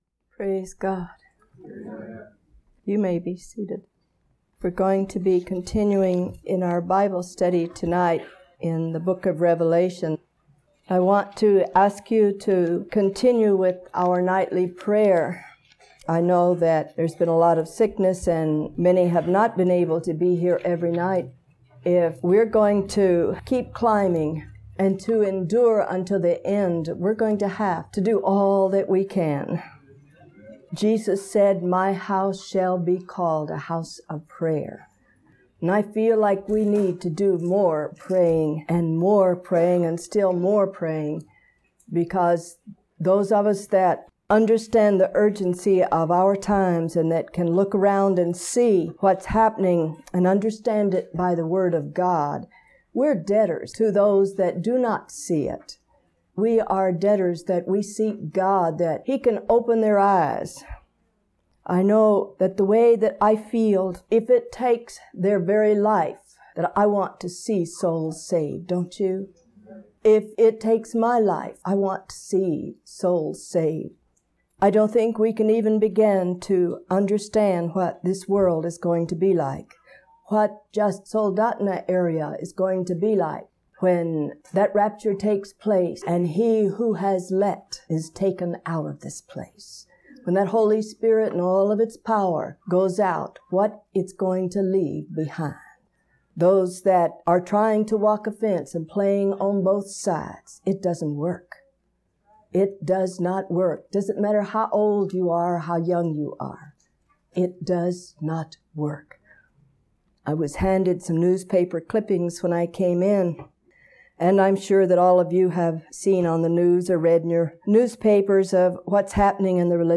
March 25, 1987 – Teaching 61 of 73